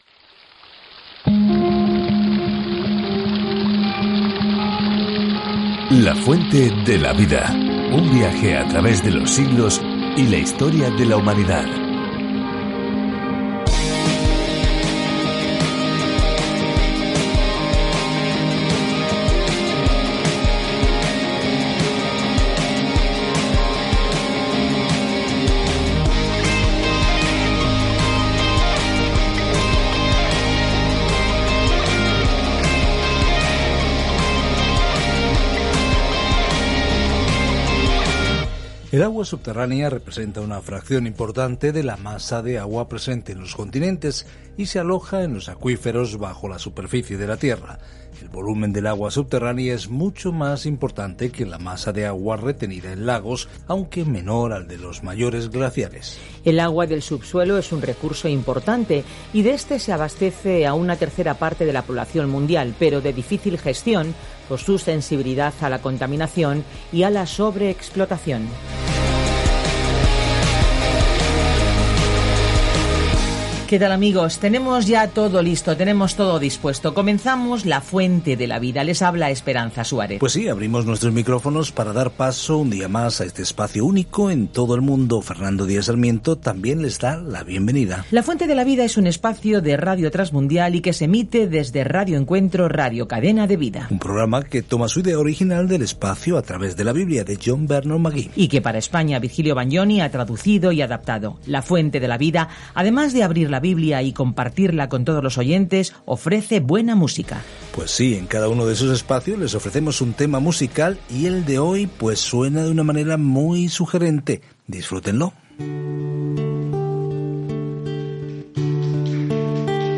Escritura NAHUM 1:1-3 Día 1 Iniciar plan Día 3 Acerca de este Plan Nahum, cuyo nombre significa consuelo, trae un mensaje de juicio a los enemigos de Dios y trae justicia y esperanza a Israel. Viaje diariamente a través de Nahum mientras escucha el estudio de audio y lee versículos seleccionados de la palabra de Dios.